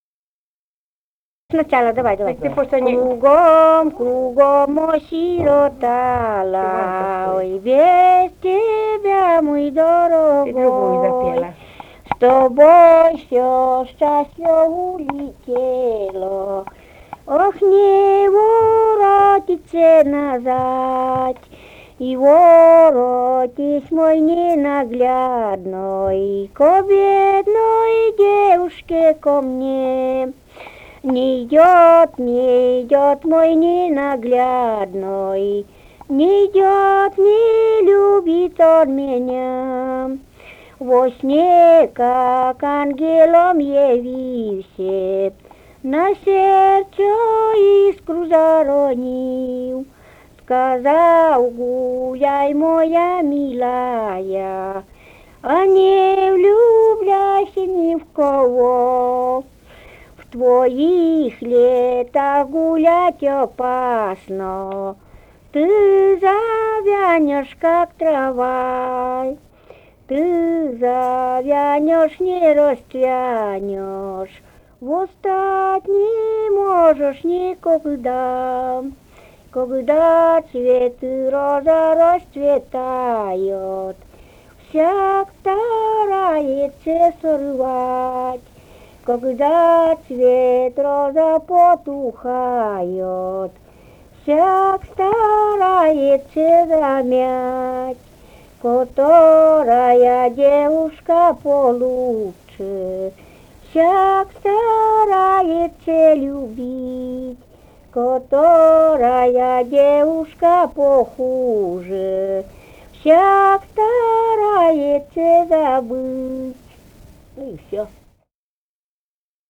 «Кругом, кругом осиротала» (лирическая).